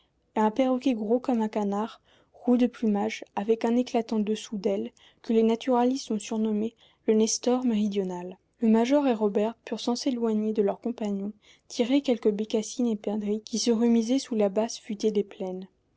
female_9854.wav